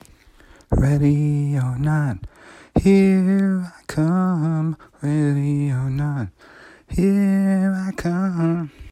fast d f# b a